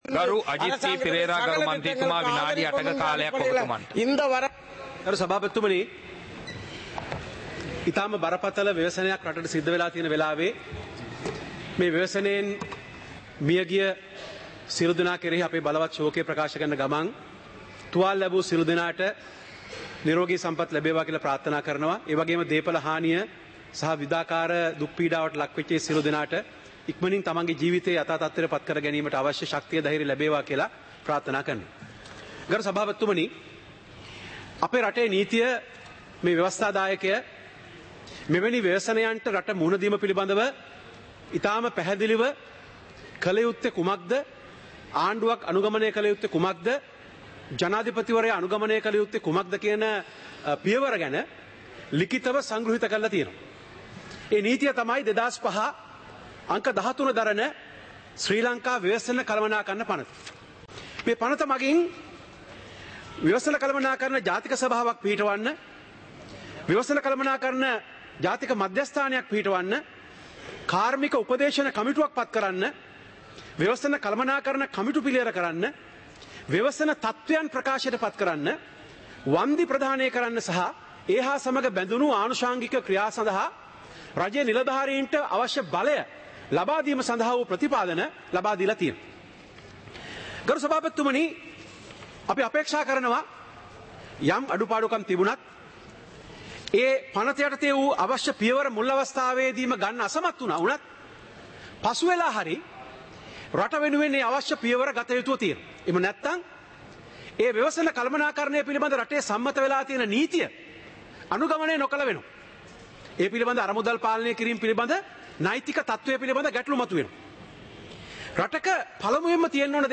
சபை நடவடிக்கைமுறை (2025-12-05)